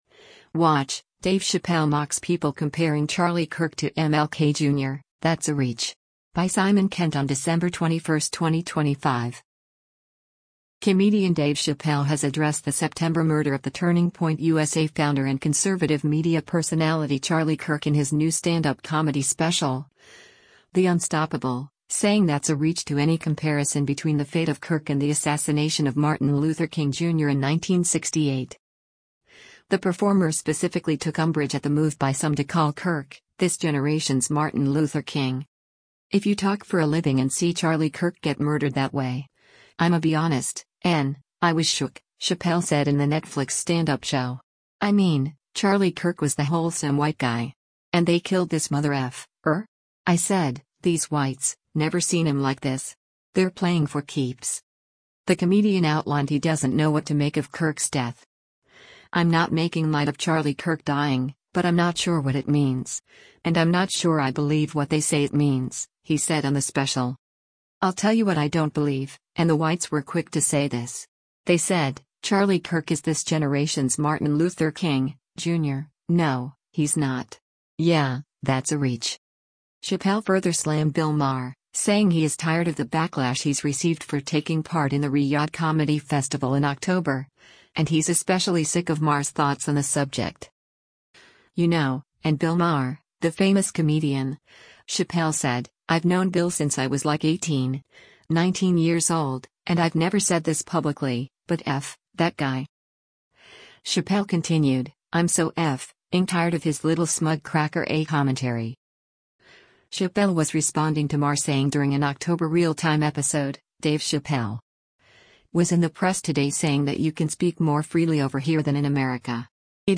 Comedian Dave Chappelle has addressed the September murder of the Turning Point USA founder and conservative media personality Charlie Kirk in his new stand-up comedy special, The Unstoppable, saying “that’s a reach” to any comparison between the fate of Kirk and the assassination of Martin Luther King Jr. in 1968.